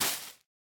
Minecraft Version Minecraft Version snapshot Latest Release | Latest Snapshot snapshot / assets / minecraft / sounds / block / spore_blossom / step1.ogg Compare With Compare With Latest Release | Latest Snapshot
step1.ogg